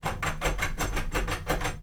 sfx_action_doorknob_02.wav